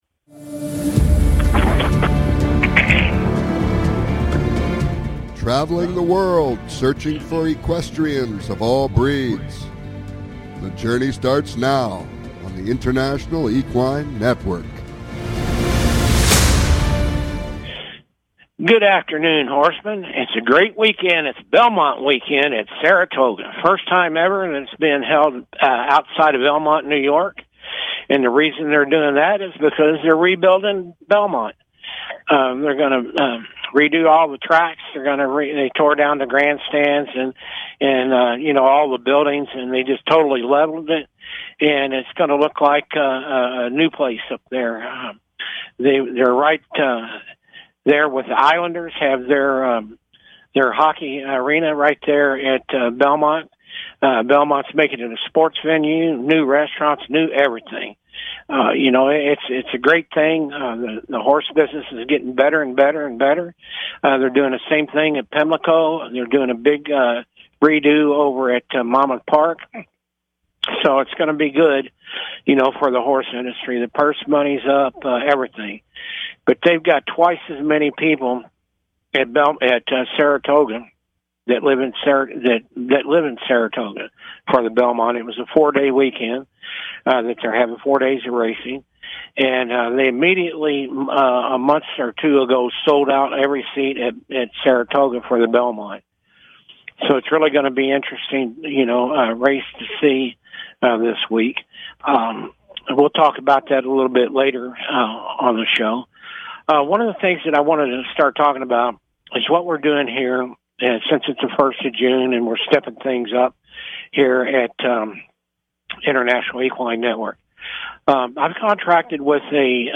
Calls-ins are encouraged!